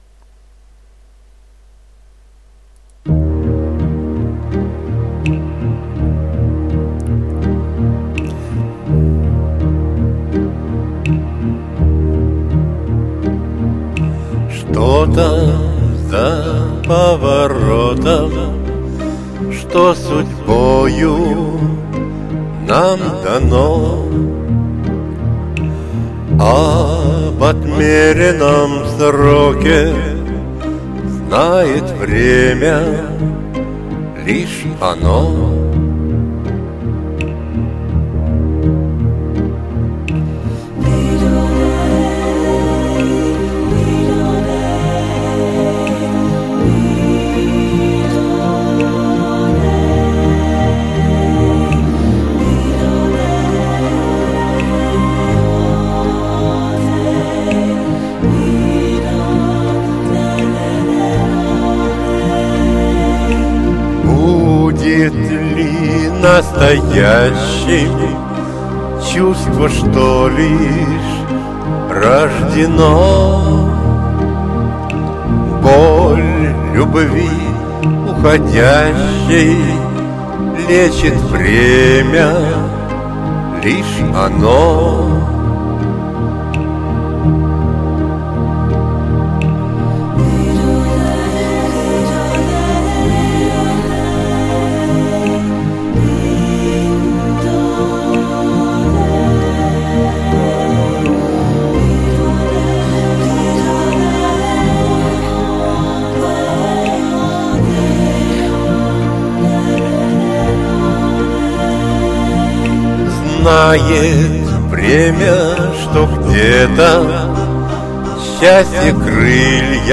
в мужском исполнении